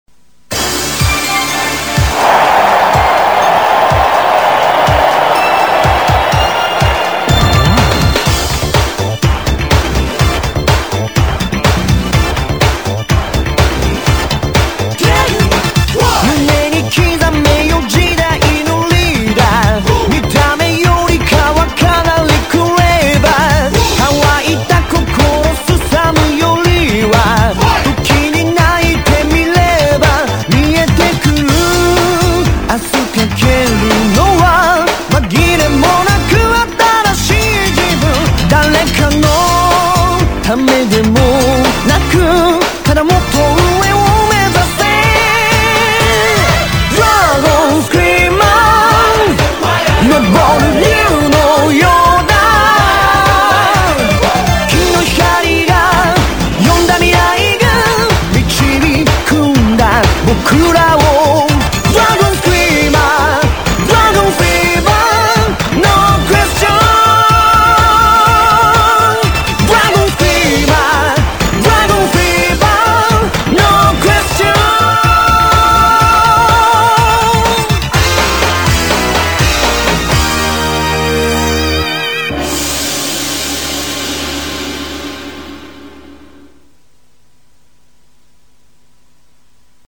c'est le génerique de la saison 1